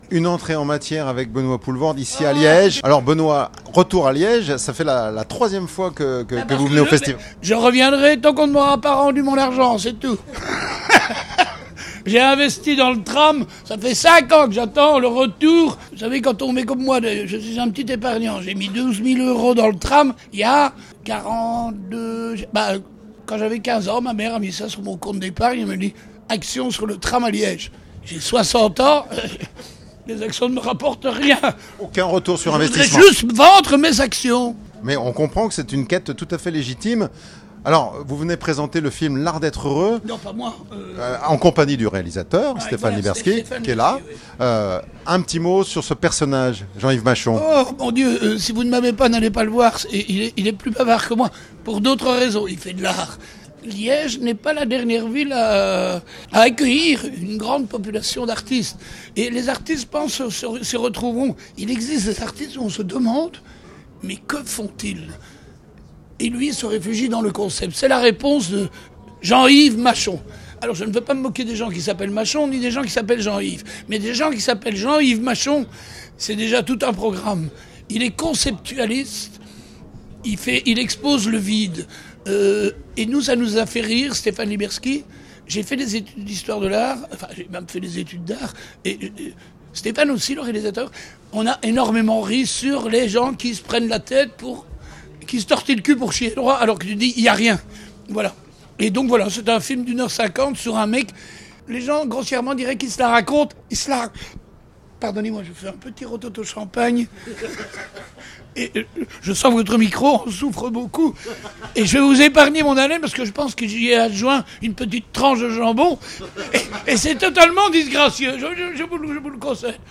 Écouter une interview de Benoît Poelvoorde, c'est comme plonger dans un torrent de pensées vives, où l'absurde côtoie la réflexion profonde et l'humour se mêle à la sincérité. Lors du Festival international du film de comédie de Liège, l'acteur a captivé l'audience avec son franc-parler inimitable, lors de la promotion de son dernier film, L'Art d'être heureux.